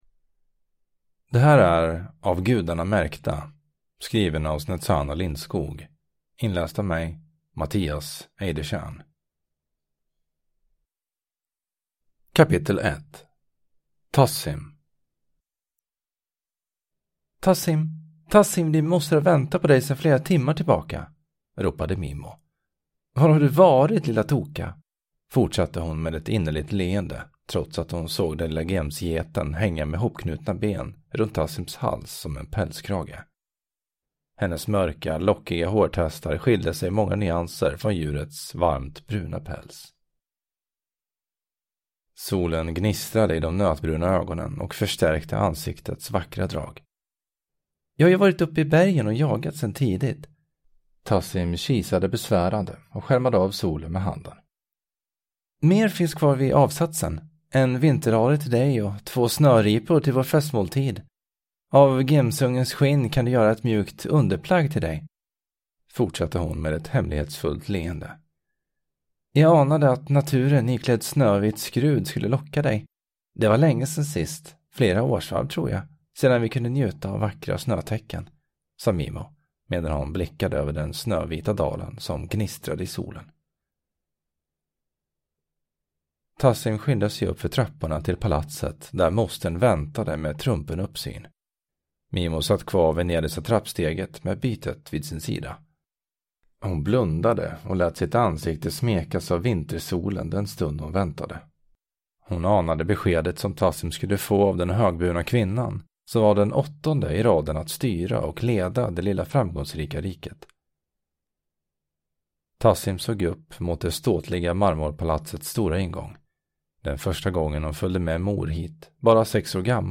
Av Gudarna märkta – Ljudbok